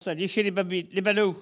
Collectif patois et dariolage
Catégorie Locution